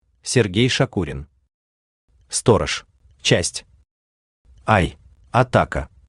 Аудиокнига Сторож | Библиотека аудиокниг